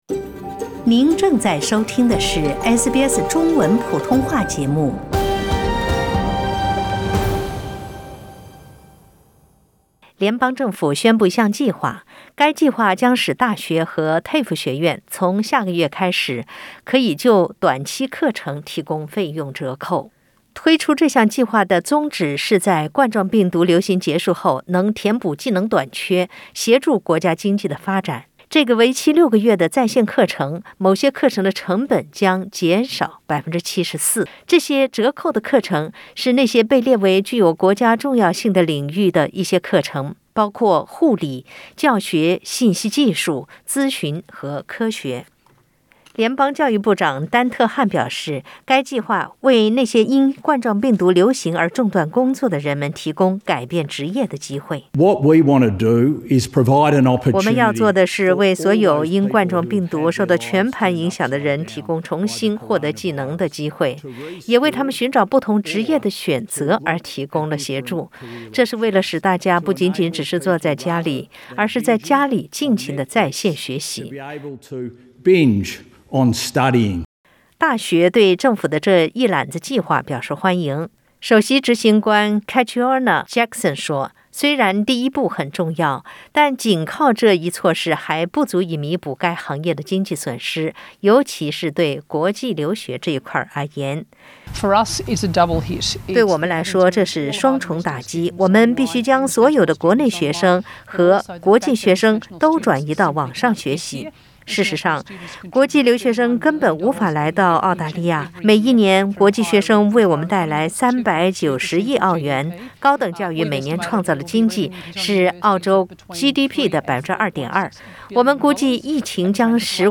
如冠状病毒持续，高等教育机构将在5月开始提供降价课程。这个为期六个月的在线课程中，某些课程的成本将减少74％。点击图片收听录音报道。